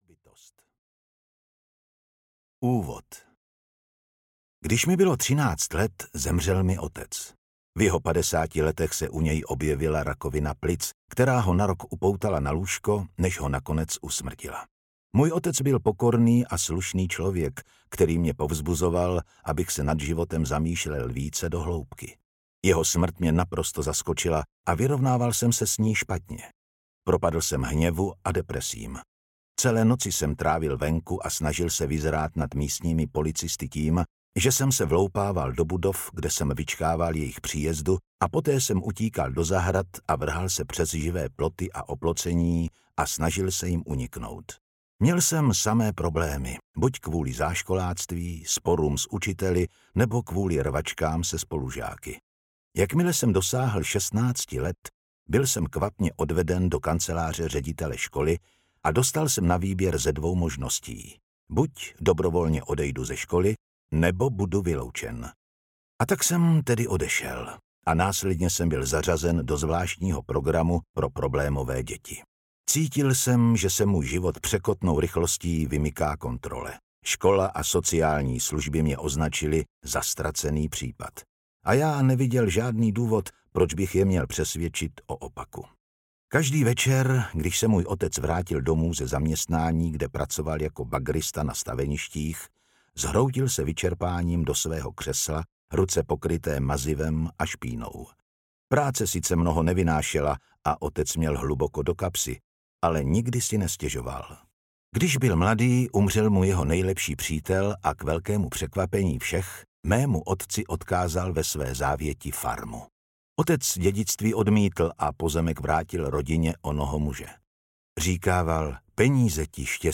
Myslet jako římský císař audiokniha
Ukázka z knihy
• InterpretZdeněk Junák